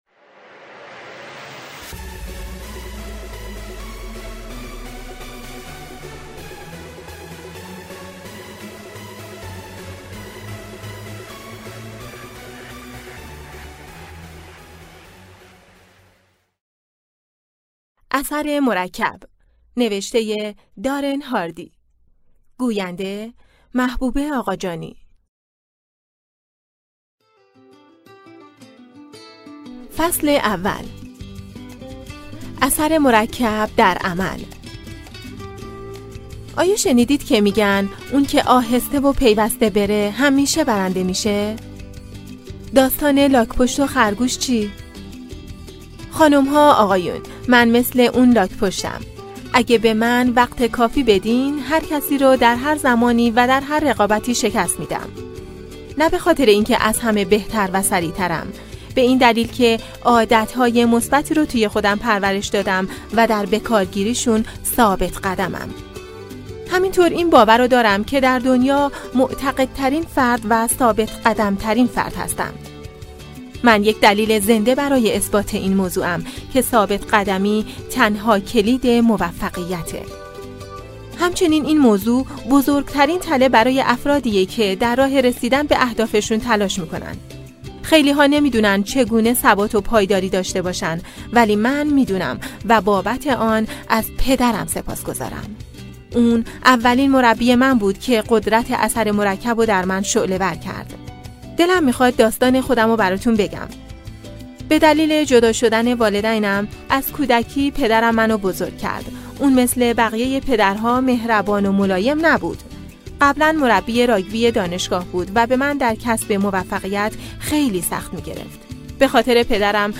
کتاب صوتی اثر مرکب 01-فصل اول 2020-10-24 Likes 15 Download Share اثر مرکب اصل به دست آوردن پاداش های بزرگ از طریق مجموعه ای از انتخاب های کوچک و هوشمندانه است.